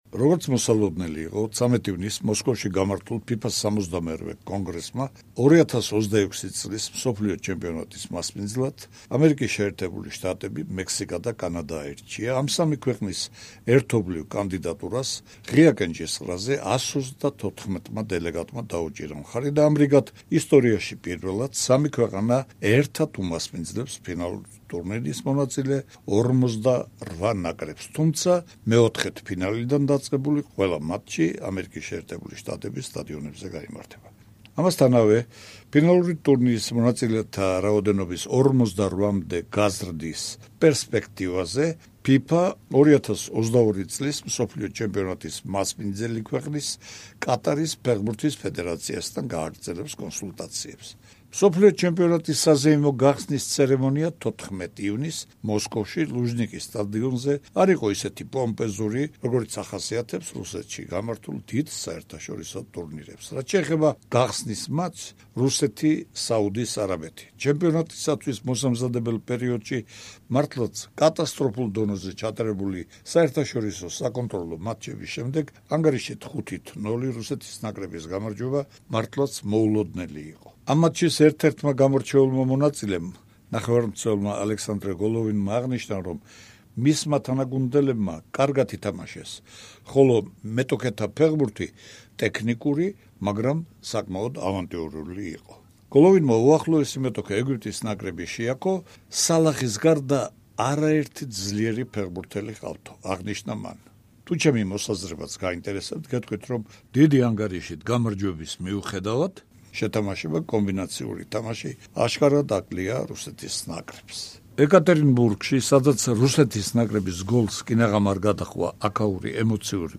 სერბეთში მიმდინარე ევროპის ჩემპიონატზე ქართველმა ნიჩბოსანმა ზაზა ნადირაძემ 200 მეტრზე გაცურვაში ოქროს მედალი მოიპოვა. თავის სამომავლო გეგმებზე ლაპარაკობს „სპორტული კვირის“ სტუმარი, ევროპის ჩემპიონი ზაზა ნადირაძე. ასევე ვისაუბრებთ წლევანდელ, რუსეთში უკვე მიმდინარე, და მომავალ მსოფლიო ჩემპიონატებზე ფეხბურთში.